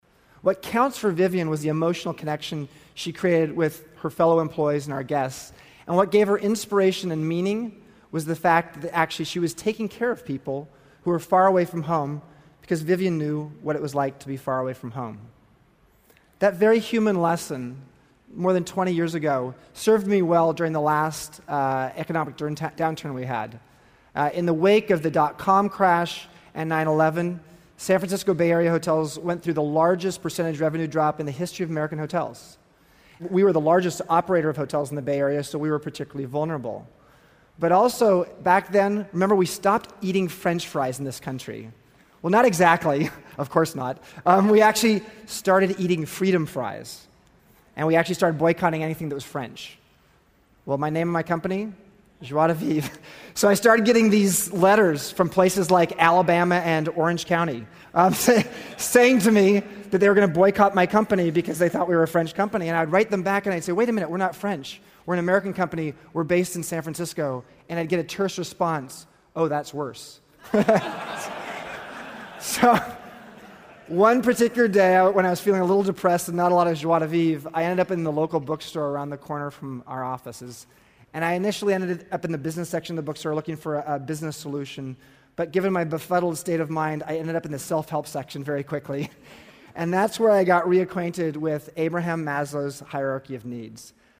这些财富精英大多是世界著名公司的CEO，在经济领域成就斐然。在演讲中他们或讲述其奋斗历程，分享其成功的经验，教人执着于梦想和追求；或阐释他们对于公司及行业前景的独到见解，给人以启迪和思考。